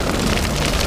flamethrower.wav